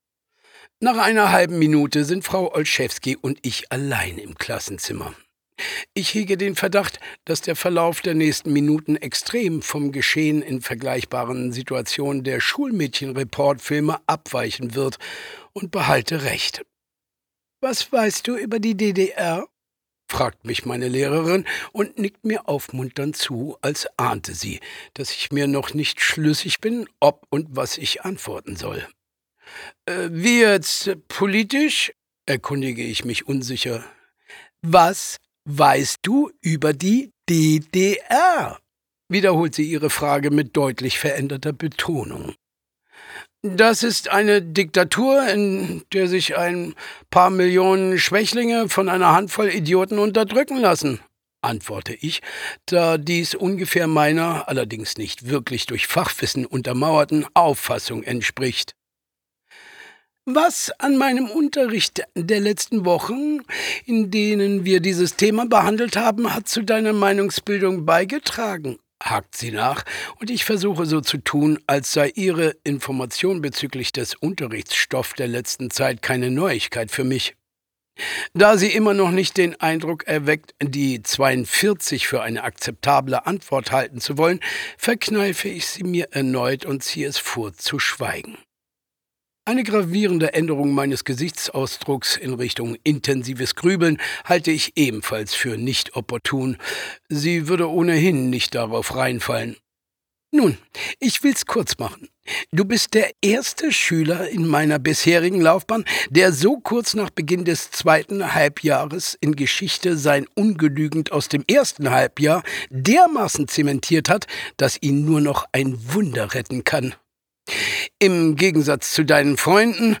Axel Prahl, Thomas Nicolai (Sprecher)
Der erst erzwungenen Briefwechsel zweier Jugendlicher aus Dortmund und Leipzig, wird zur Dauer-Beziehung über zweieinhalb Jahrzehnte. Axel Prahl und Thomas Nicolai ziehen genüsslich alle Facetten ihres sprachlichen und darstellerischen Vermögens.